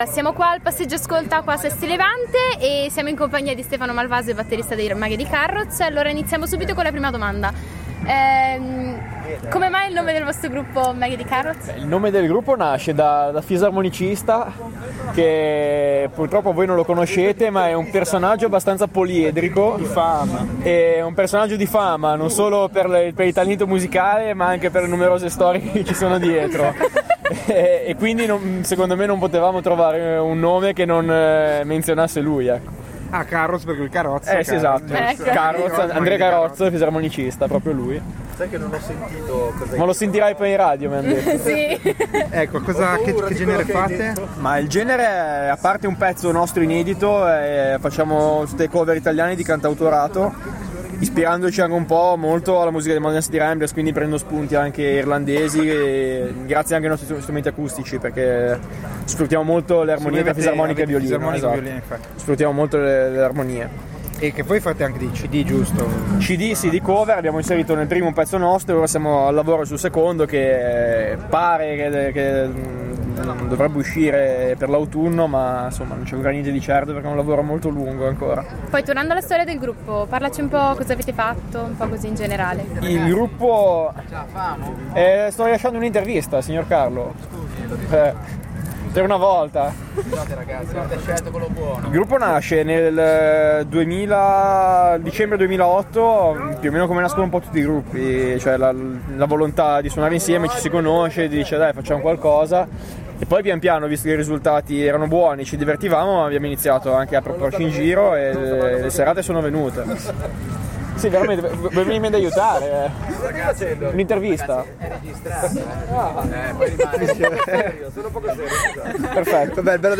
Intervista a Maghi di Carroz
Intervista ai Maghi di Carroz, band cover di artisti italiani